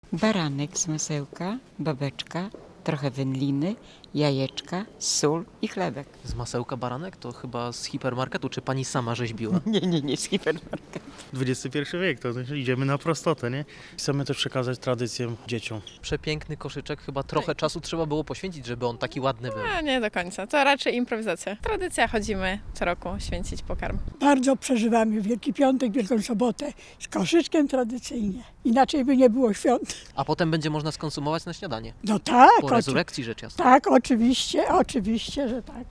w bazylice św. Brygidy w Gdańsku.